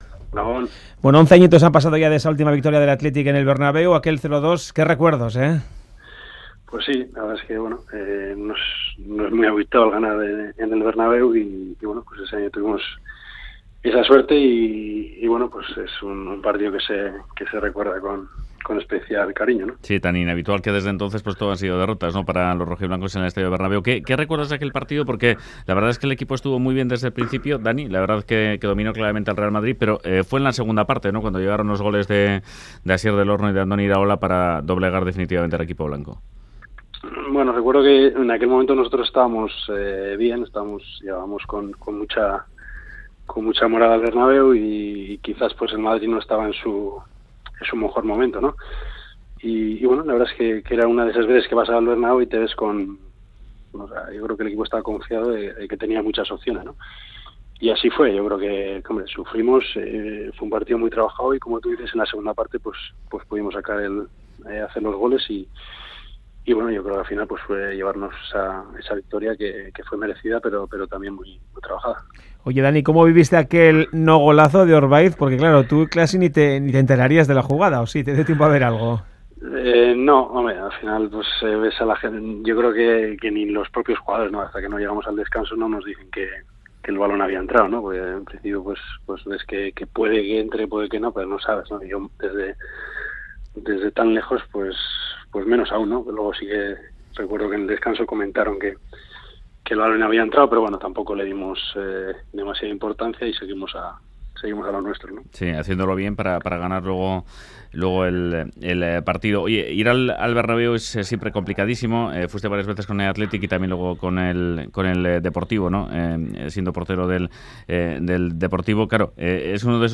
Entrevista a Dani Aranzubia, sobre el Real Madrid-Athletic Club